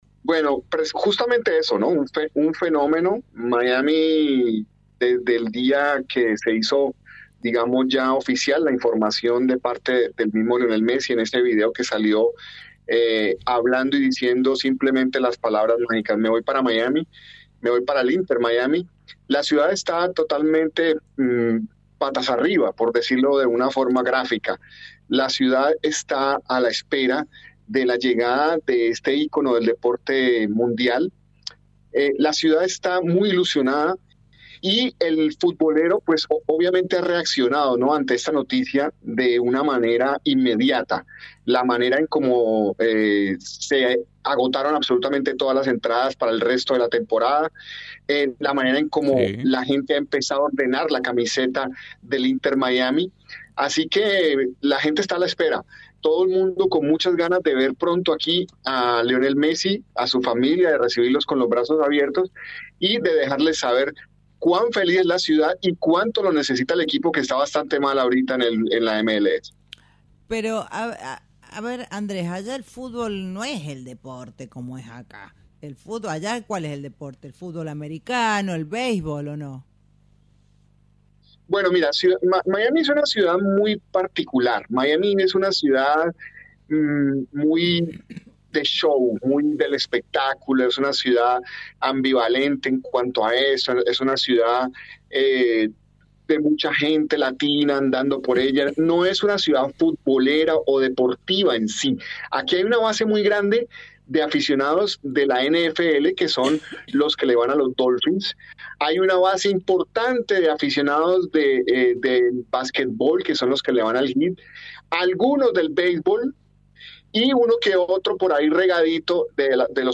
periodista colombiano